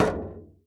VoxelEngine / res / content / base / sounds / steps / metal_3.ogg
metal_3.ogg